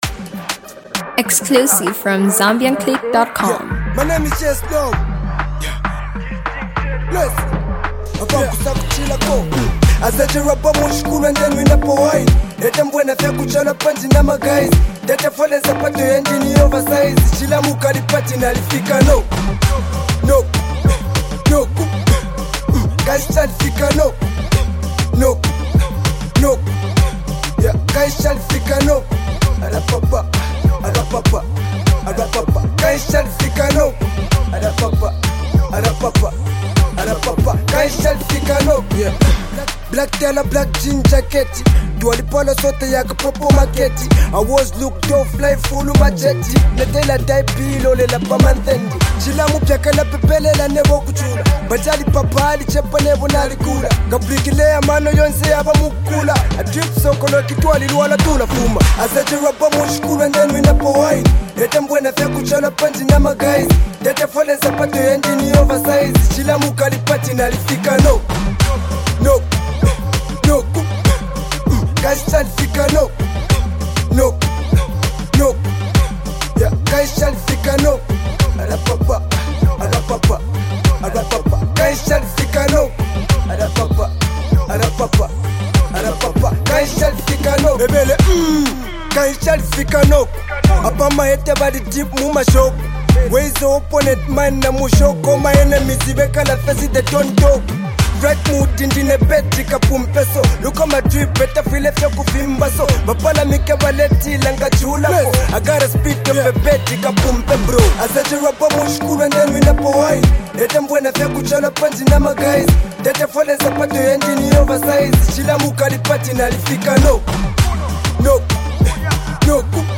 Zambian newwave